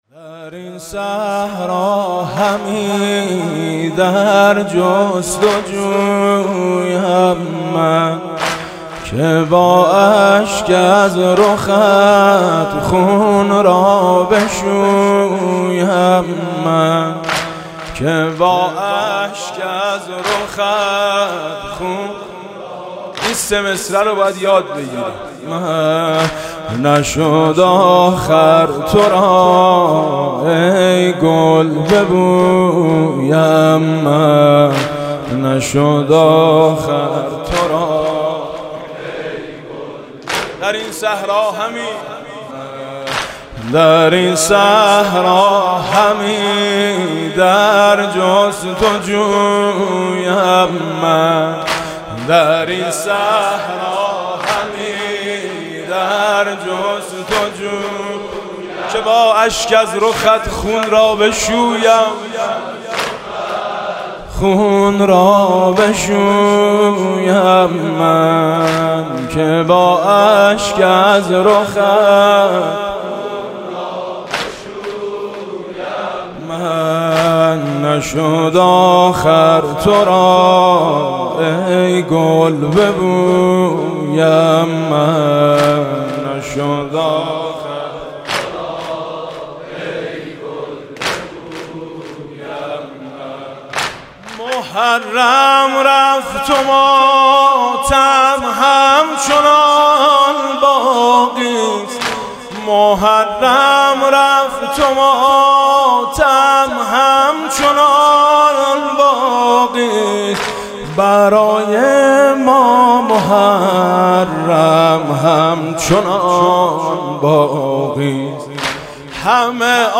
ویژه شهادت امام حسن عسکری (زمینه)